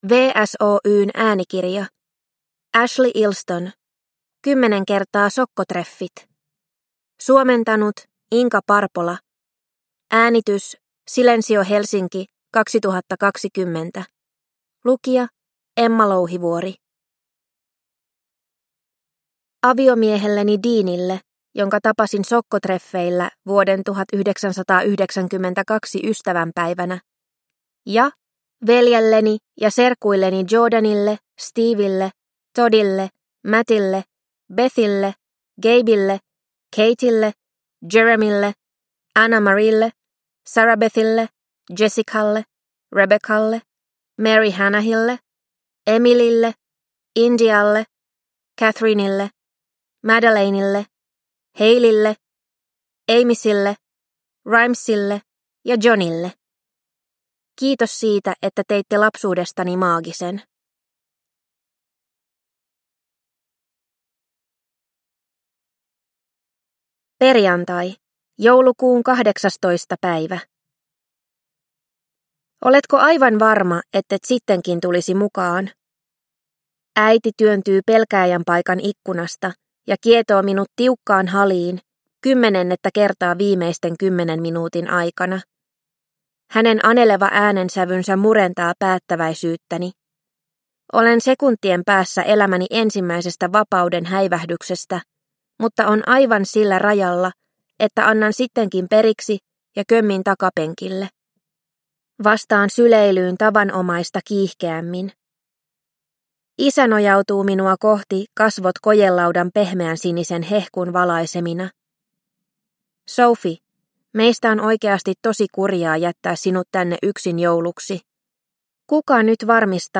10 x sokkotreffit – Ljudbok – Laddas ner